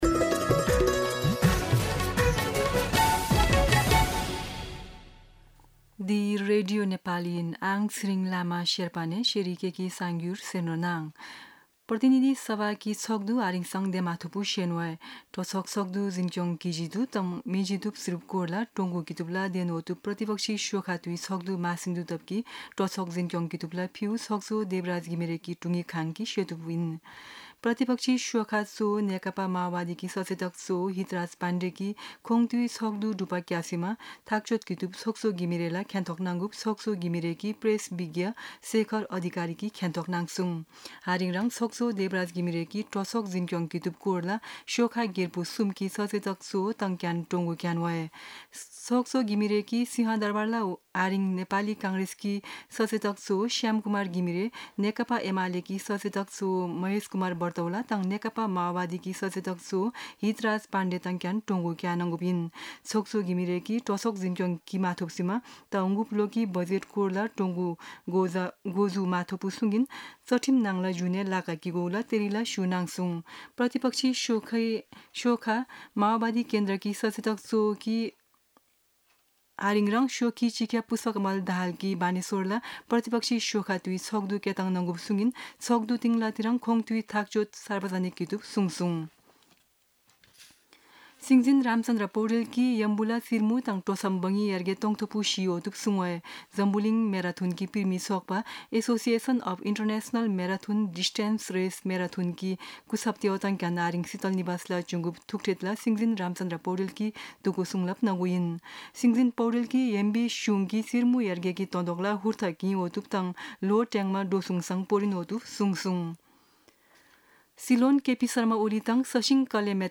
शेर्पा भाषाको समाचार : २५ जेठ , २०८२